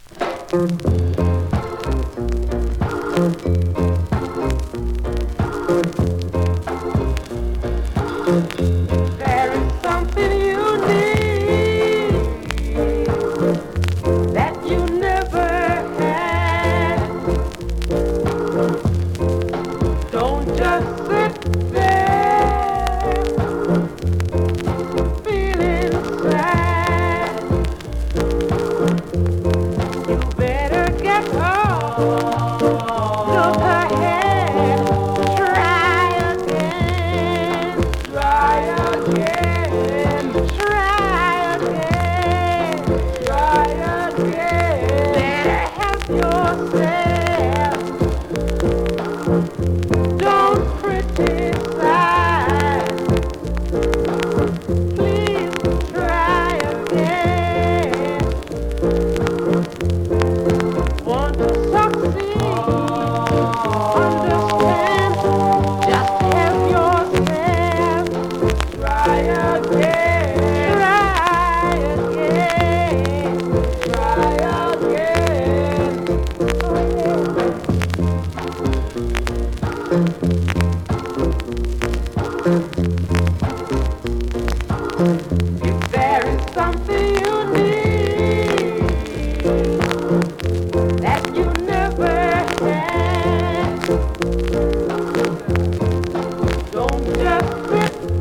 スリキズ、ノイズそこそこありますが